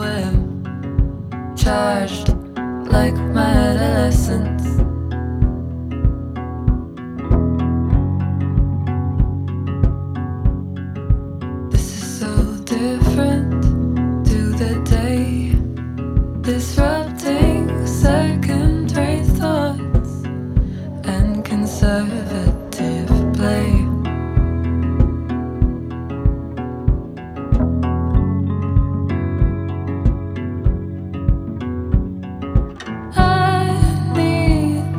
Жанр: Поп музыка
Vocal, Pop, Indo Pop